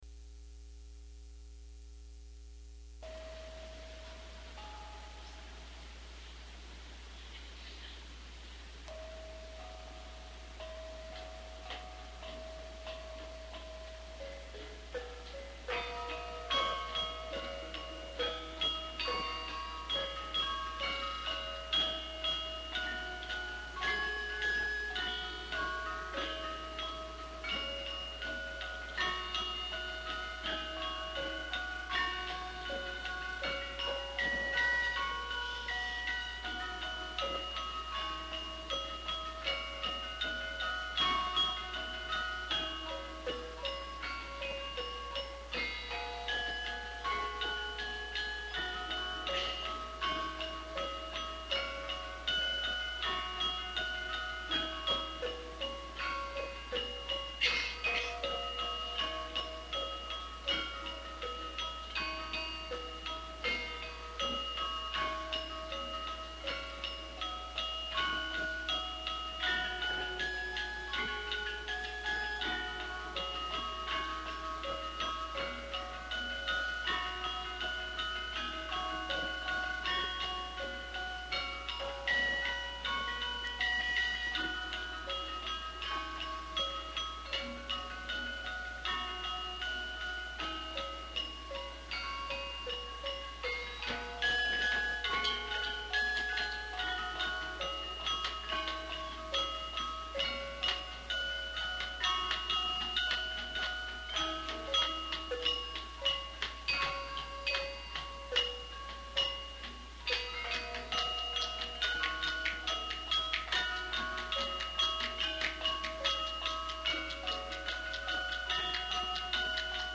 Group:  Gamelan Nyai Saraswati
Chapel Hill Museum
This popular piece has two sections, the second an expansion of the first and featuring a chorus.
04_Ladrang_Asmaradana_slendro_manyura.mp3